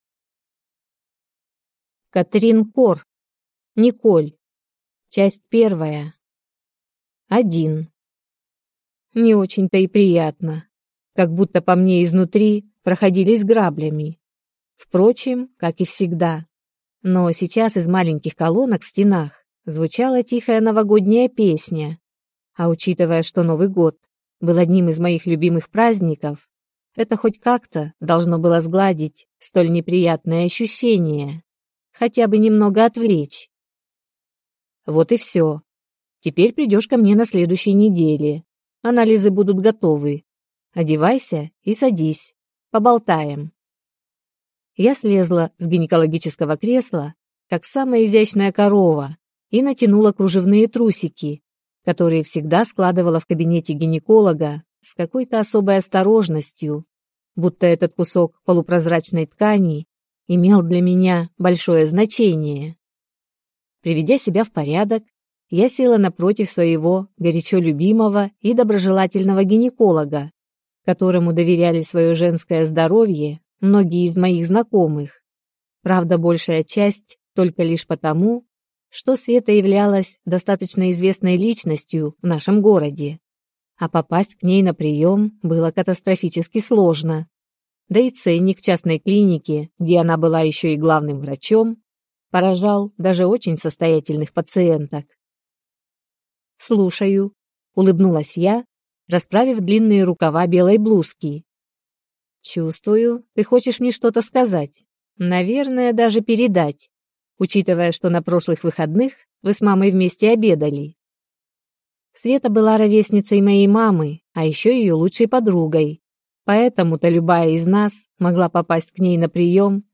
Aудиокнига Николь.